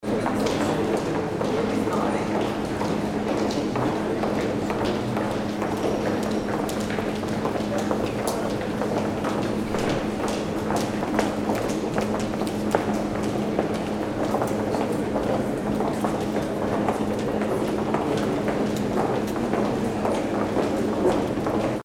Gemafreie Sounds: Flughafen
mf_SE-5615-pedestrians_in_a_hall_1.mp3